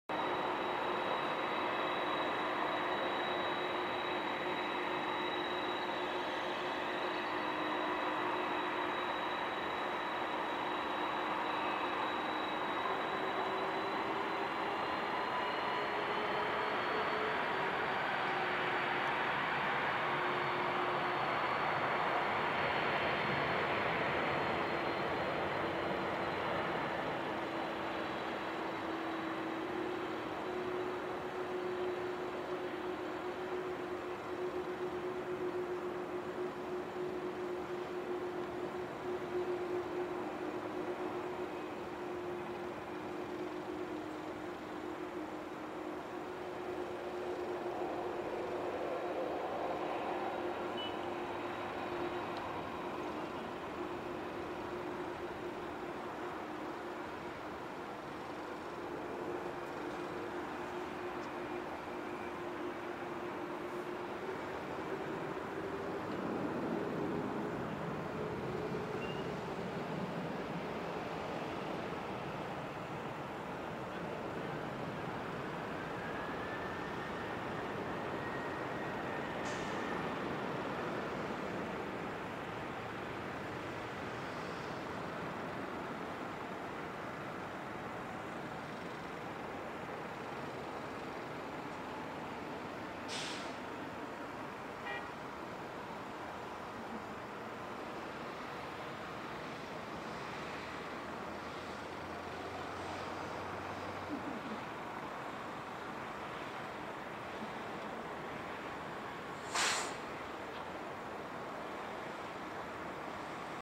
Kalitta Air Boeing 747 LAX Night Takeoff!
Route: Los Angeles (LAX) to Anchorage (ANC) Aircraft: Boeing 747-481F